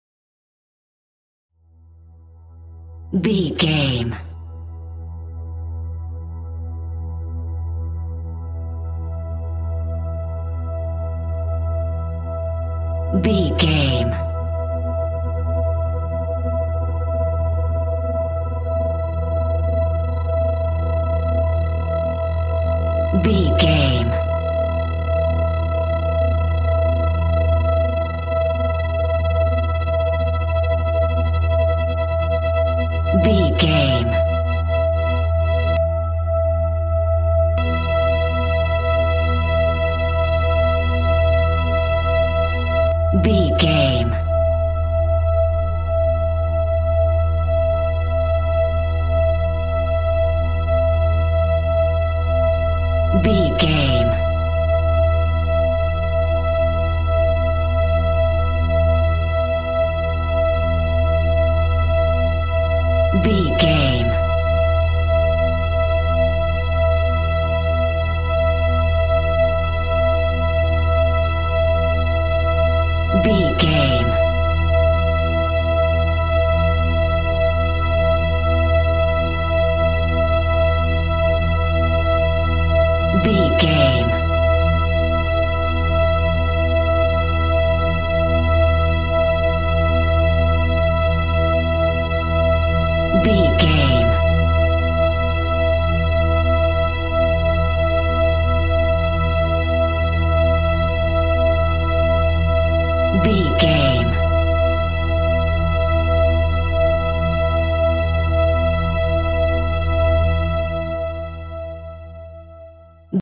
In-crescendo
Aeolian/Minor
Slow
ominous
dark
haunting
eerie
synthesiser
Synth Pads
atmospheres